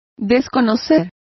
Complete with pronunciation of the translation of disowned.